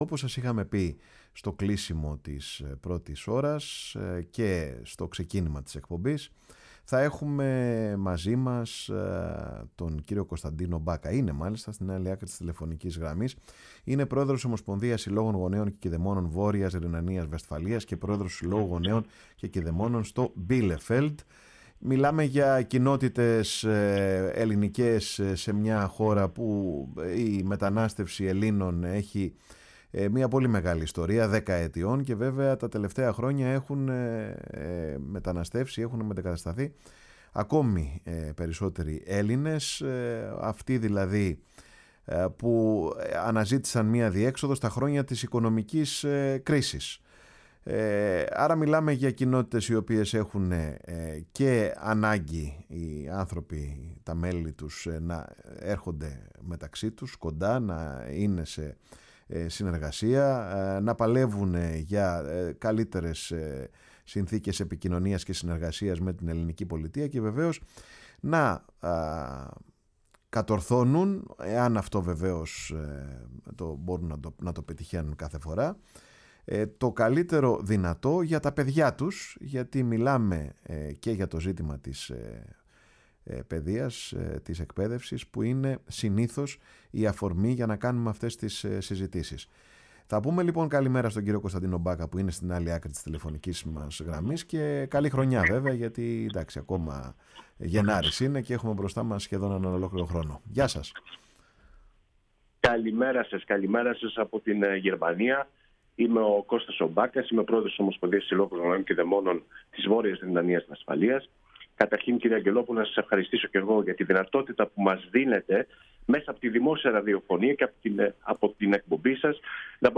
στη συνέντευξη που έδωσε στη Φωνή της Ελλάδας και στην εκπομπή “Πάρε τον Χρόνο σου”